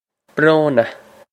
Bro-na
This is an approximate phonetic pronunciation of the phrase.